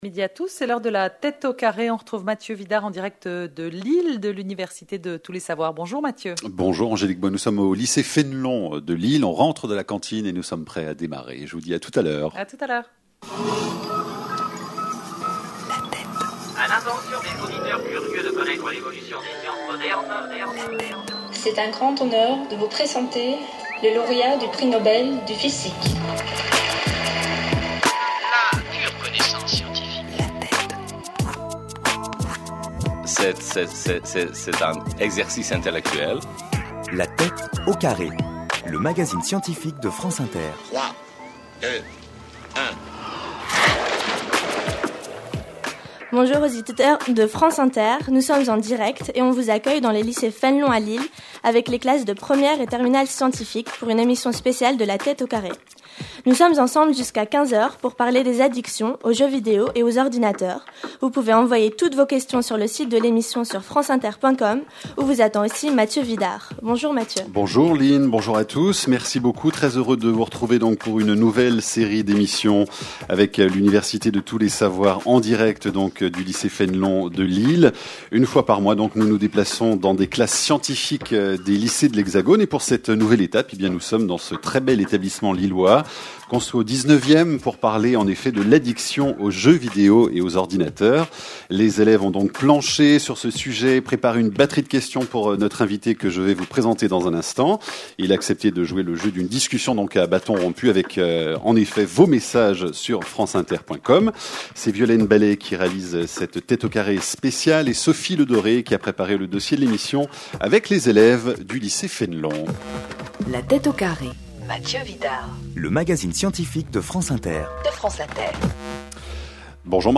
Une conférence de l'UTLS au lycée en partenariat avec France Inter Dans le cadre de l’émission de Mathieu Vidard - La tête au carré - donne l’occasion une fois par mois à des lycéens de débattre avec un scientifique sur un sujet thématique.